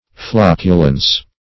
Flocculence \Floc"cu*lence\, n.